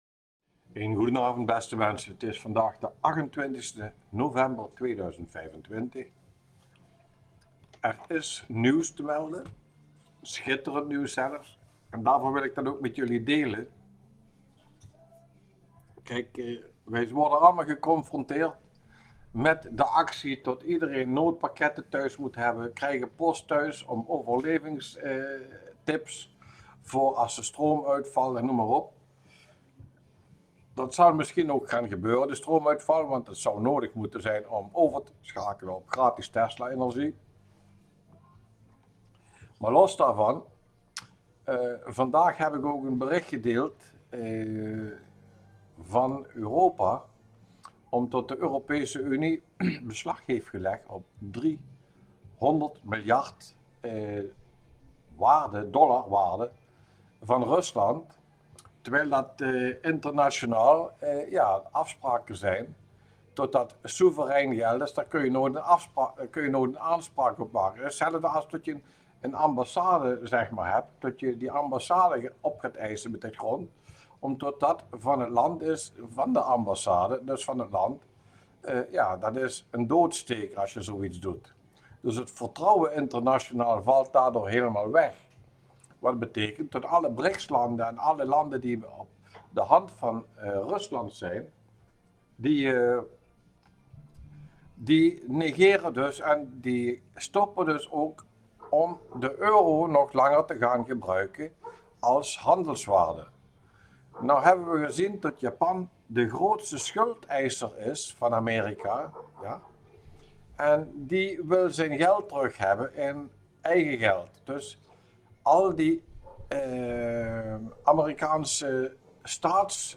BELANGRIJK AUDIOBERICHT